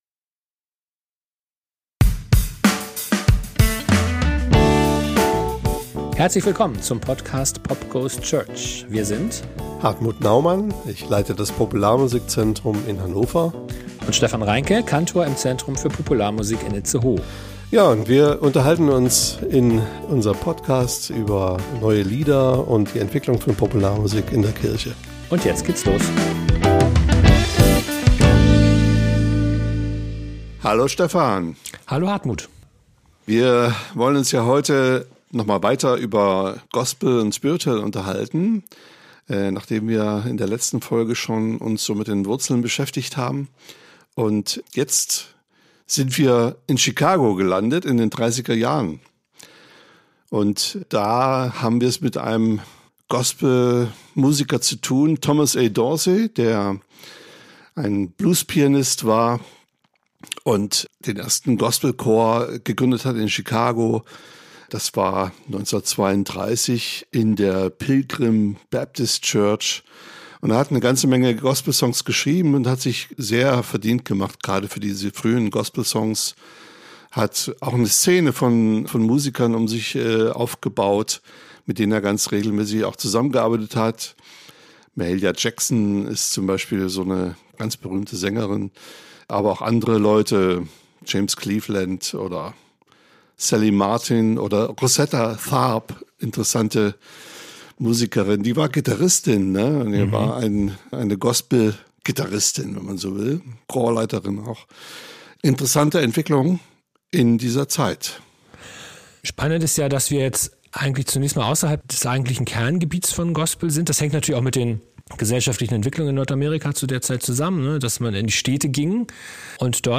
In unsere zweiten Folge zur Geschichte der Gospelmusik schauen wir auf ihre Bedeutung in der Gegenwart und wie es zu dieser besonderen Bedeutung für die christliche Popularmusik kam. Als Interviewgast haben wir den Gospelkantor